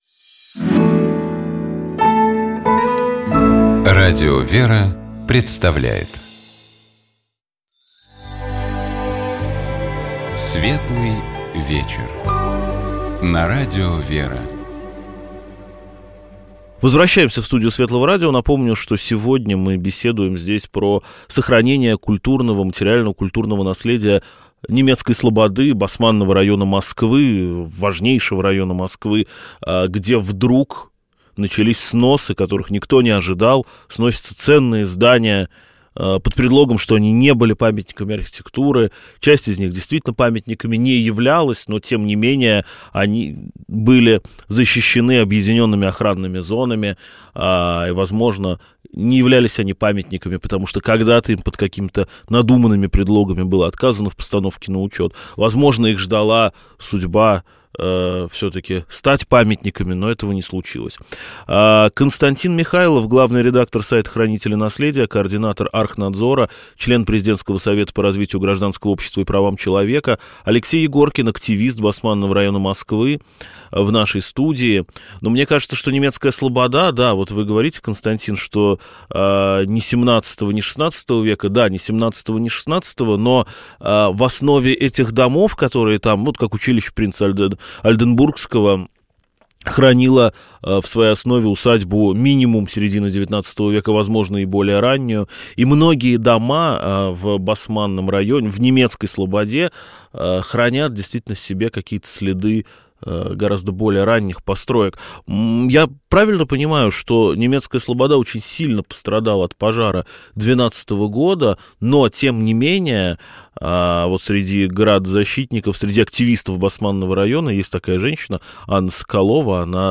Мы говорили с нашими гостями о ситуации в Немецкой слободе — историческом районе Москвы, которому угрожает уничтожение.